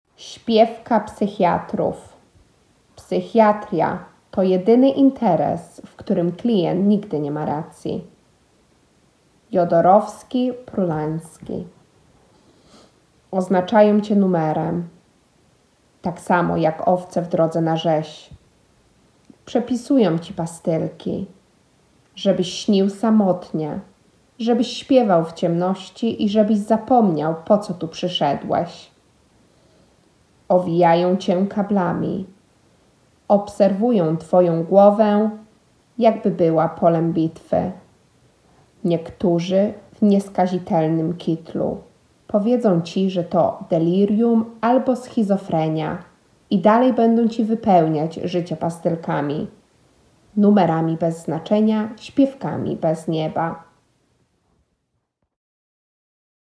Wiersz